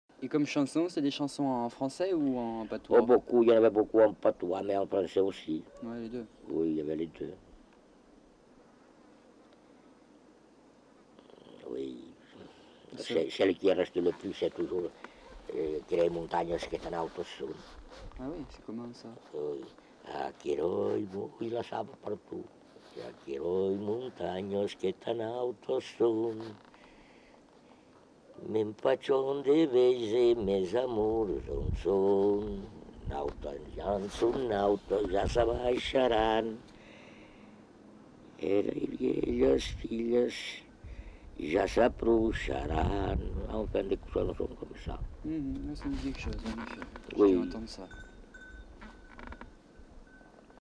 Aire culturelle : Couserans
Genre : chant
Effectif : 1
Type de voix : voix d'homme
Production du son : chanté